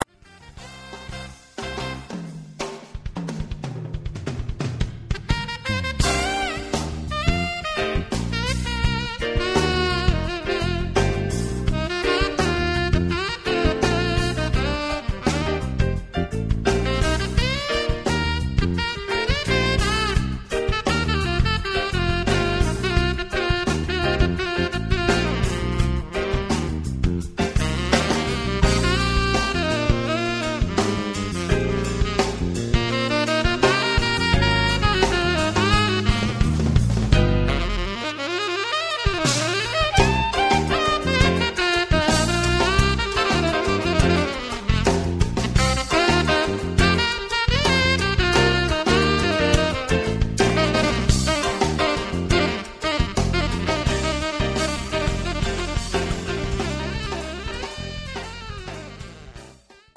It is high energy, but not too esoteric.